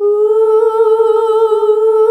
UUUUH   A.wav